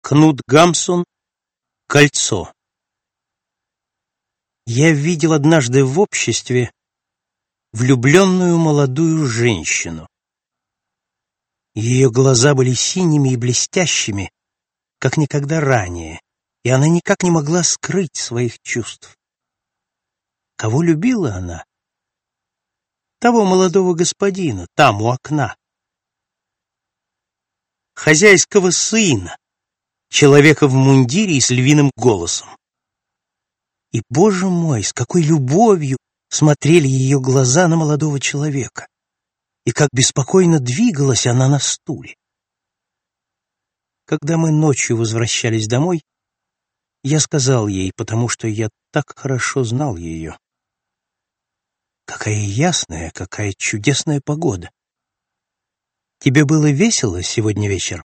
Аудиокнига Рабы любви. Избранное | Библиотека аудиокниг